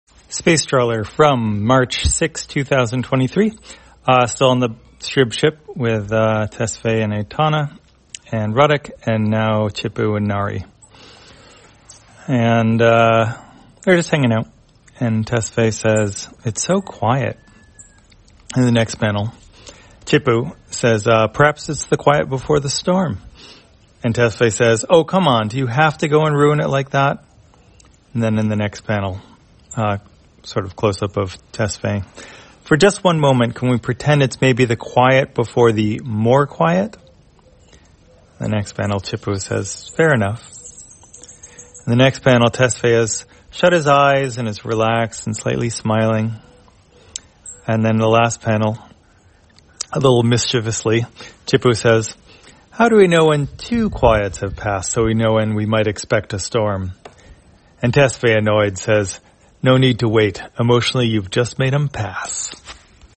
Spacetrawler, audio version For the blind or visually impaired, March 6, 2023.